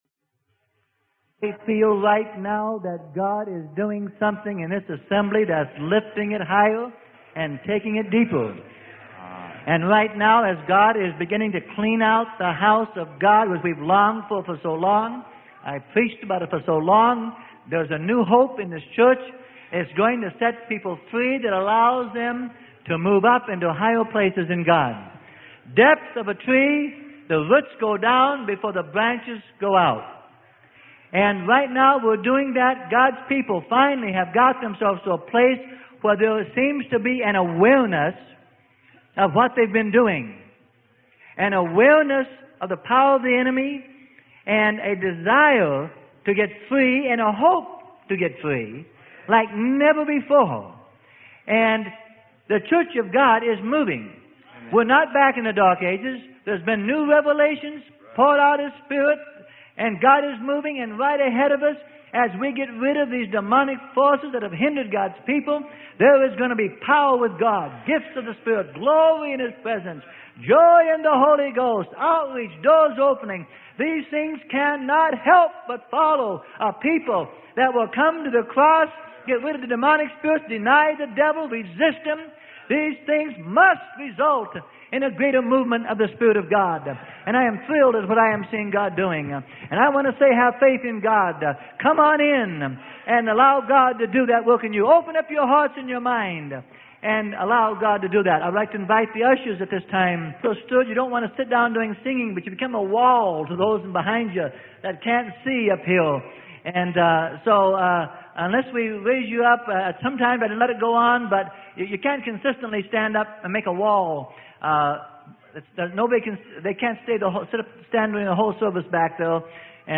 Sermon: War with the Devil on Planet Earth - Freely Given Online Library